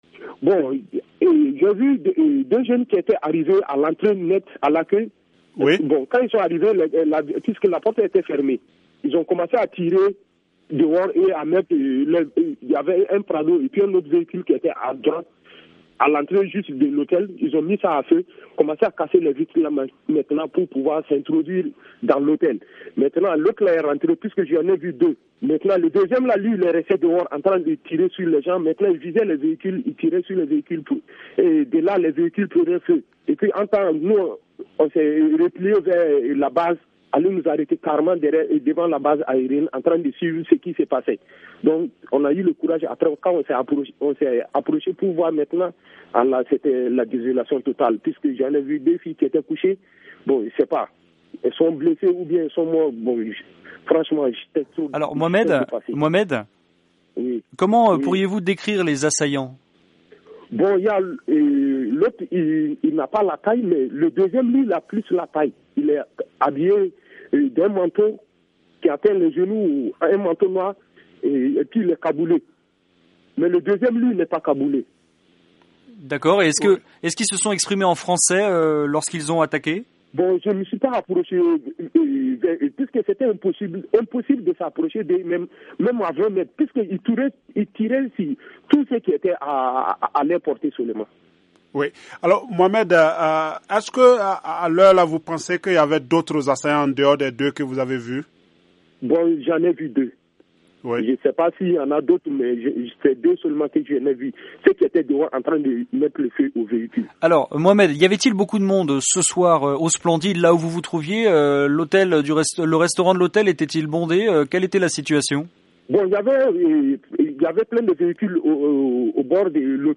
Un autre témoin interrogé par VOA Afrique